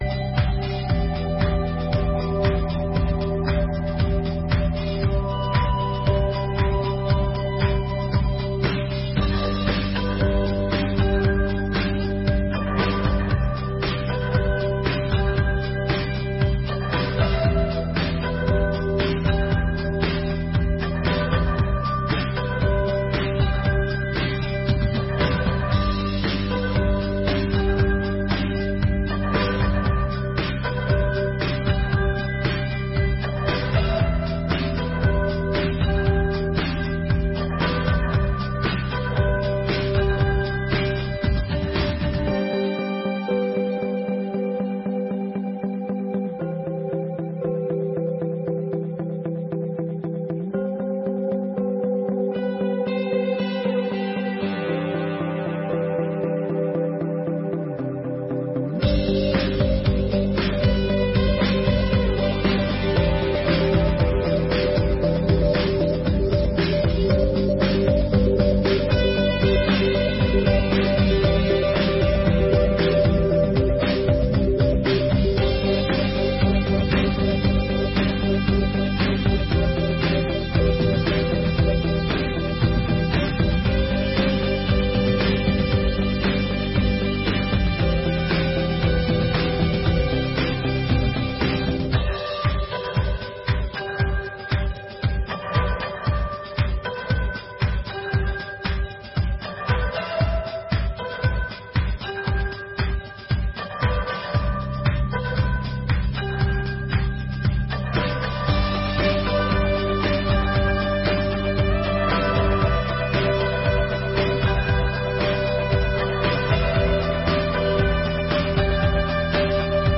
Audiência Pública: Discussão sobre o aumento das áreas de zona azul.